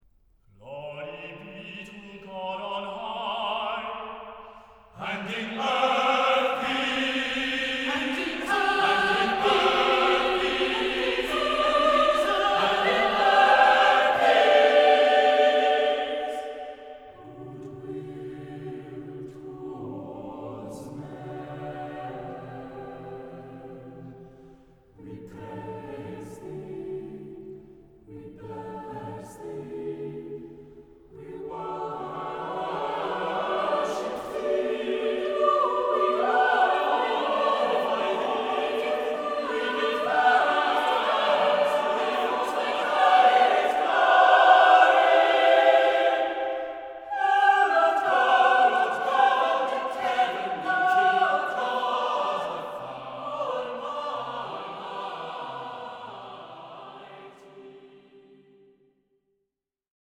(organ)